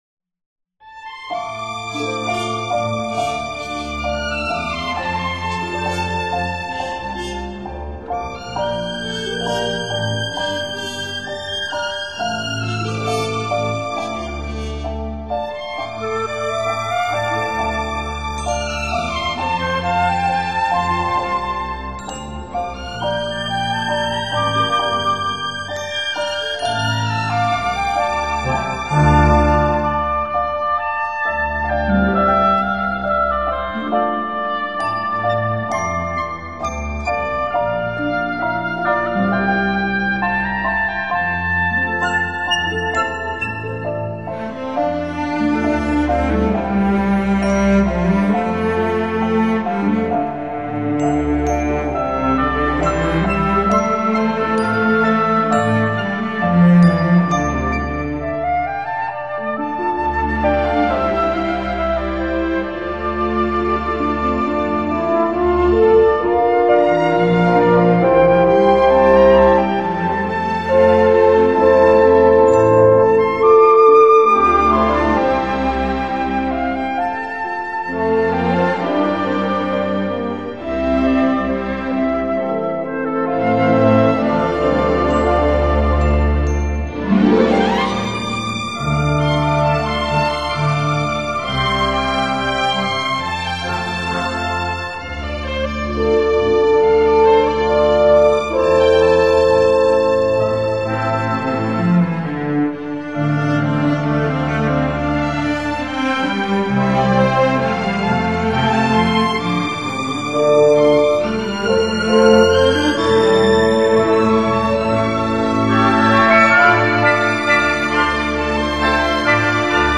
演奏，在奥地利具有先进录音设备与技术的专业录音棚录制完成。